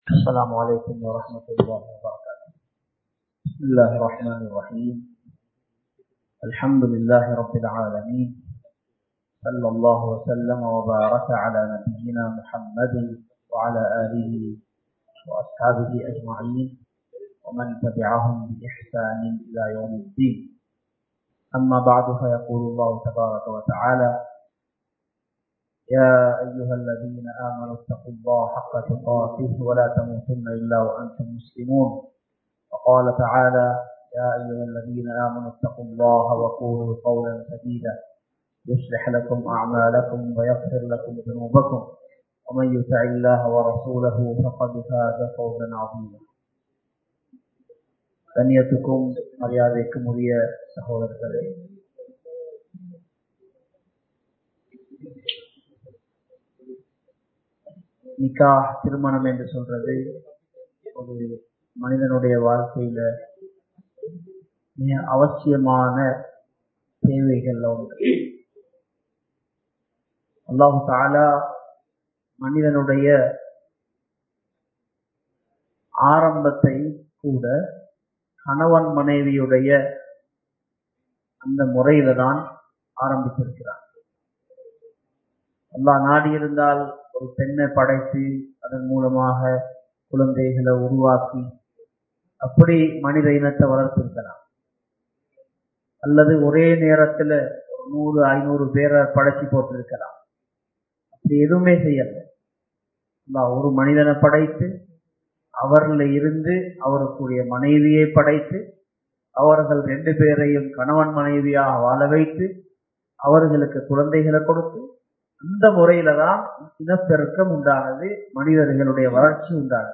குடும்ப வாழ்க்கையில் சந்தோசம் வேண்டுமா? | Audio Bayans | All Ceylon Muslim Youth Community | Addalaichenai